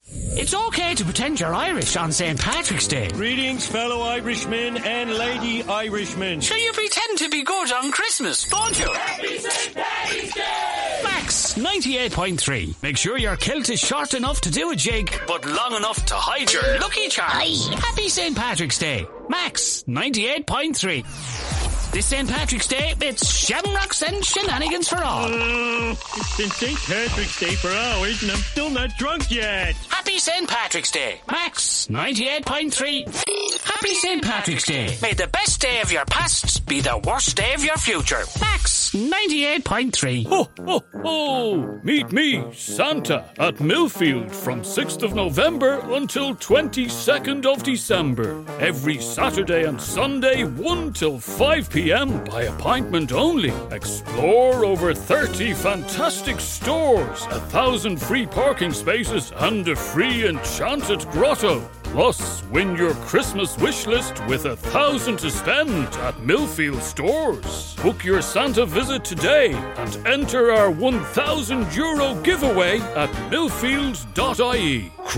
Inglés (irlandés)
Interpretaciones
Estudio: Estudio y cabina insonorizados